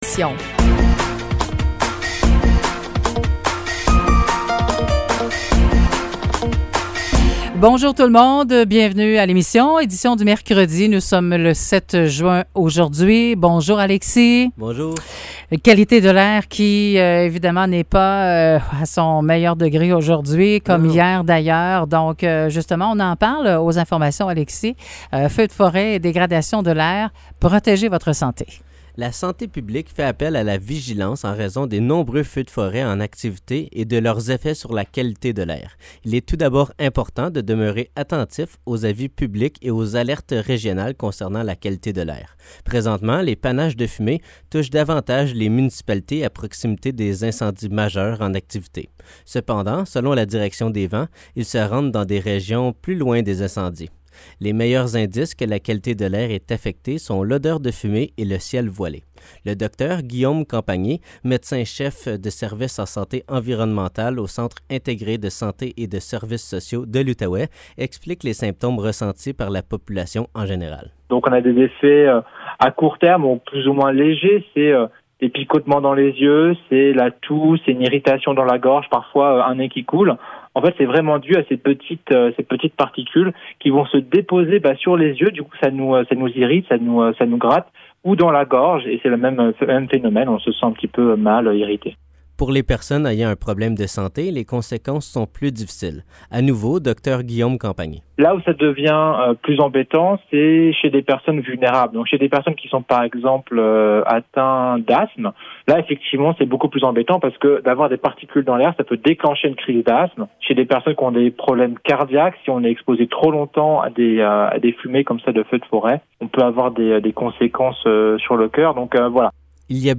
Nouvelles locales - 7 juin 2023 - 9 h